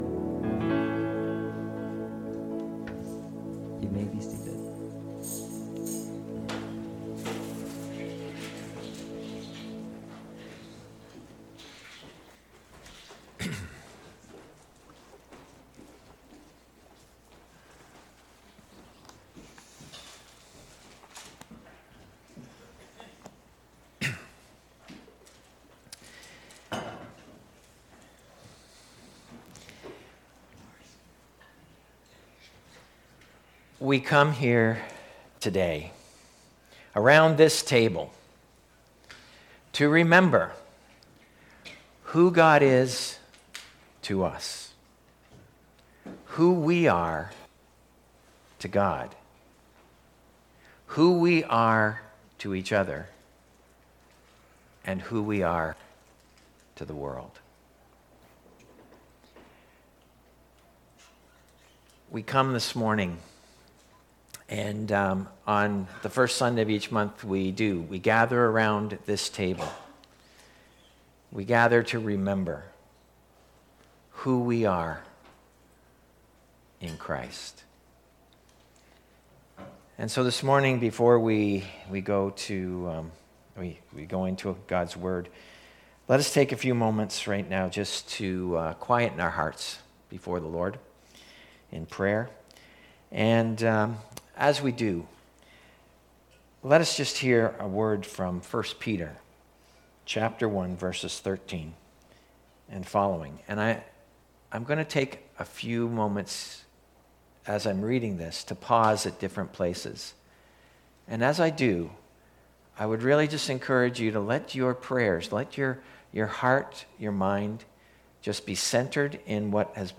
Sermons | Edmison Heights Baptist